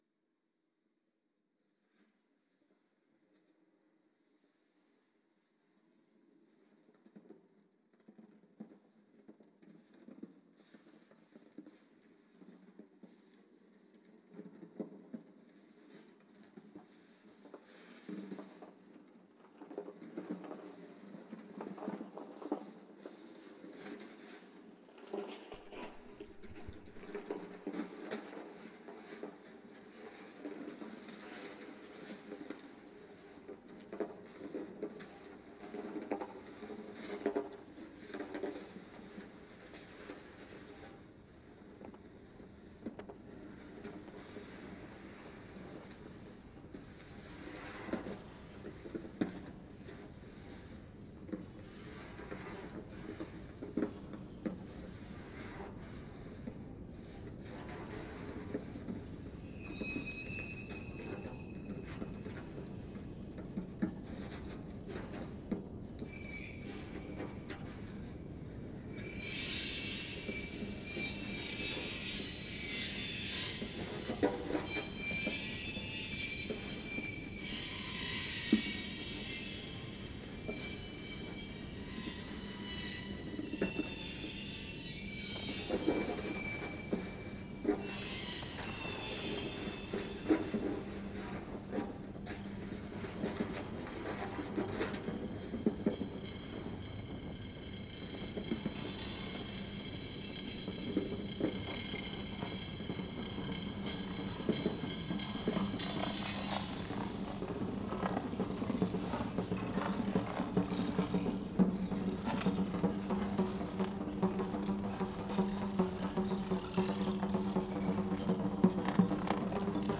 2013 Sound design for choreography